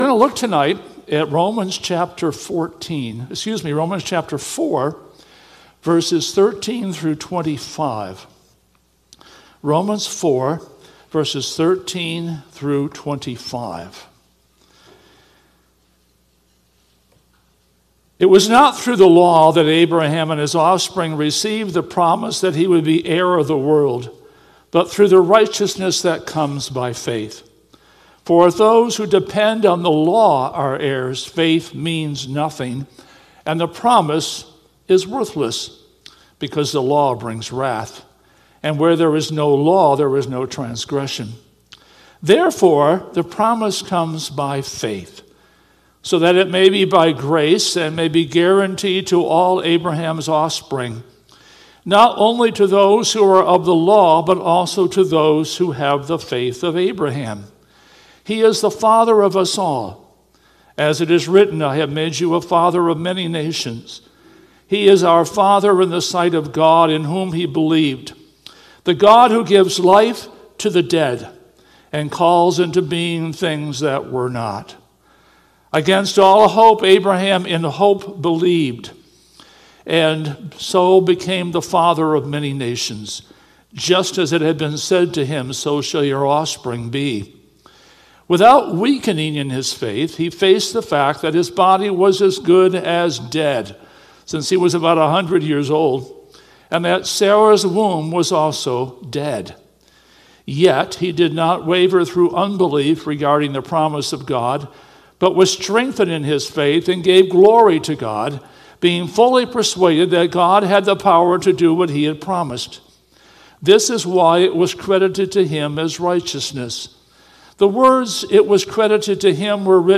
Sermon Recordings | Faith Community Christian Reformed Church
“Hoping Against Hope” July 20 2025 P.M. Service